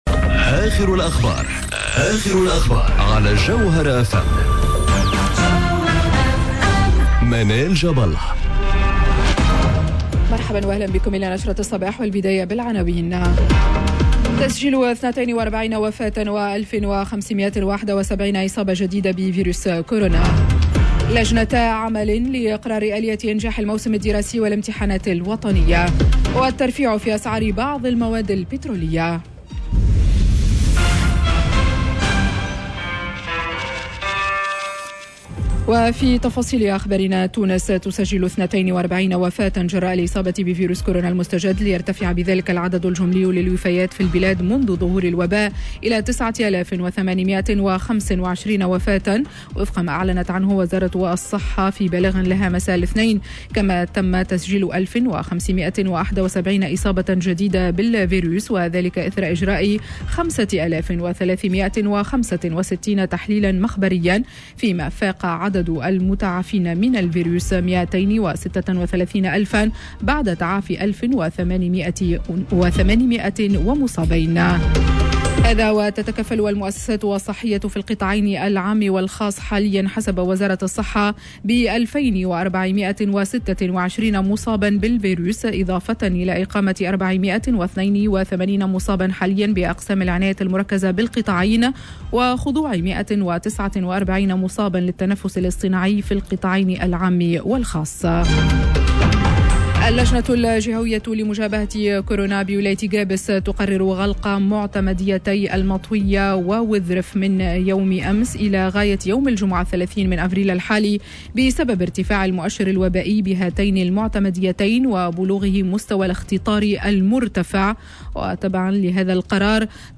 نشرة أخبار السابعة صباحا ليوم الثلاثاء 20 أفريل 2021